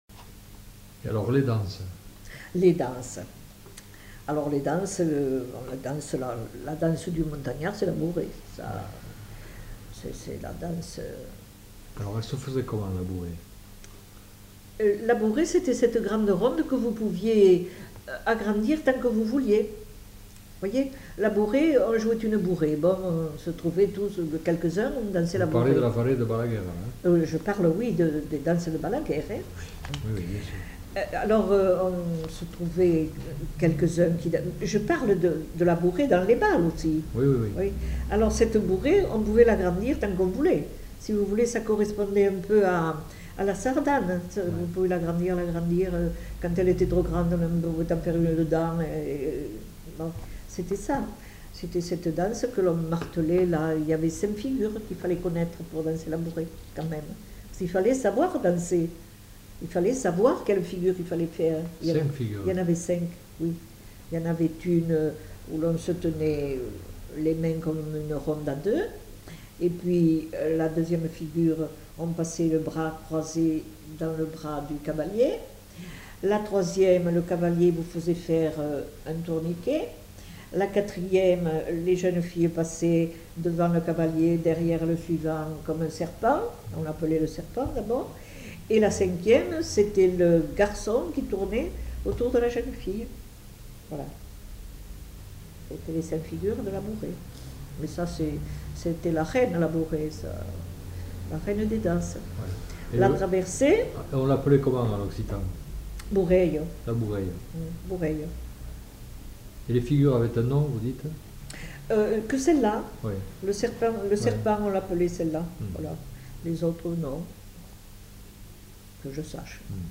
(informateur)
Genre : témoignage thématique